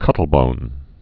(kŭtl-bōn)